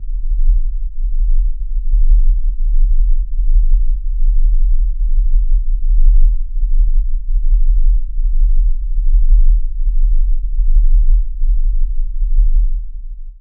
56-LOWPULS-R.wav